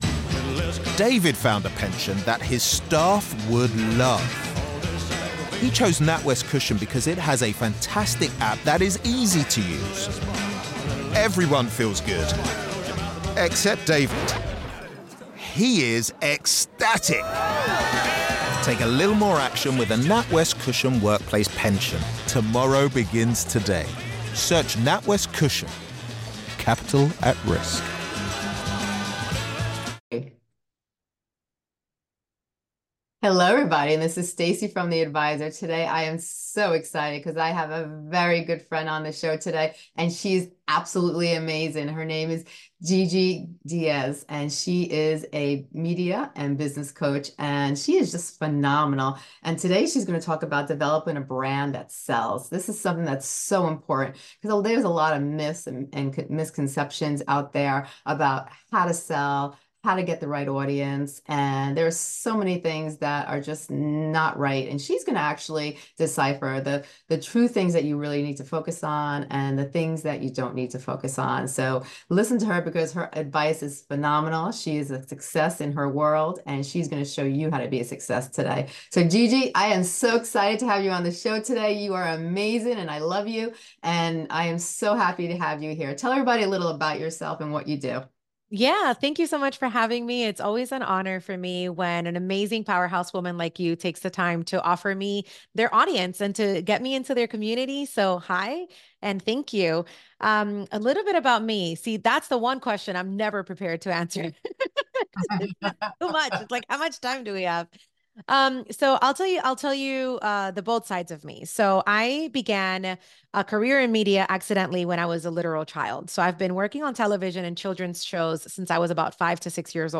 an empowering conversation